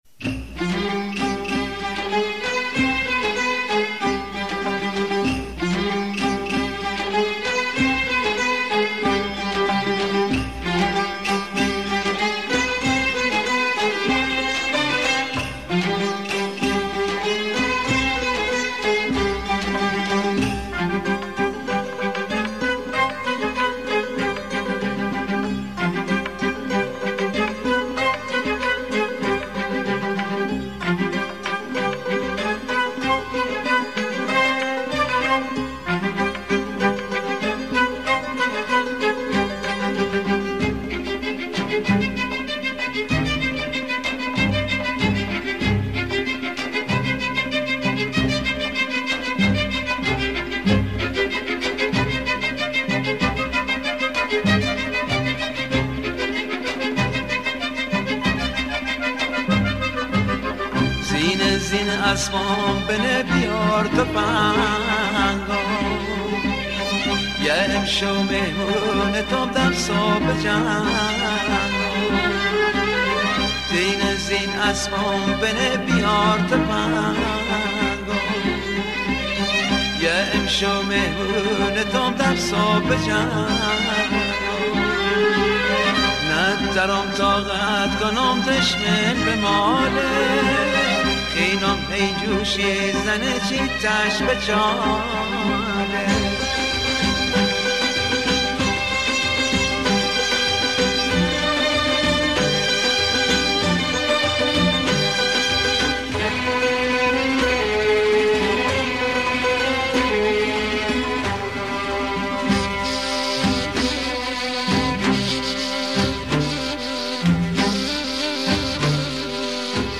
بختیاری